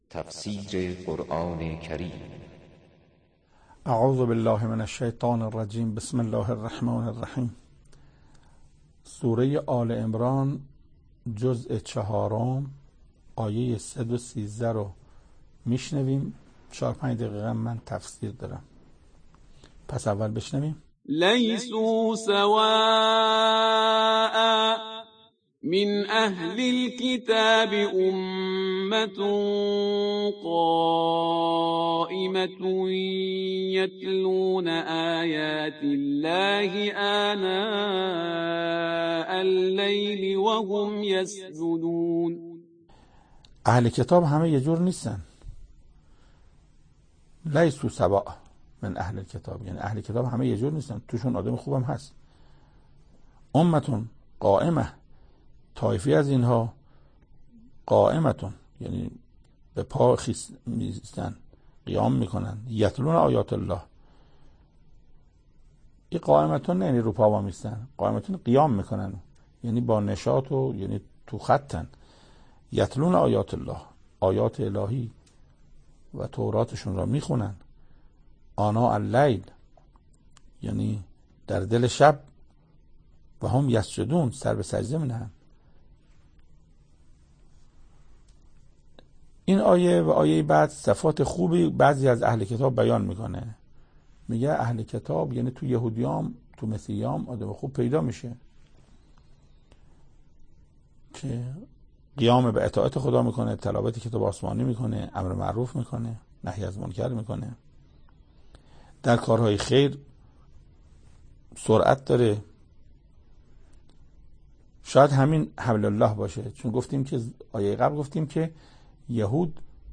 سخنرانی محسن قرائتی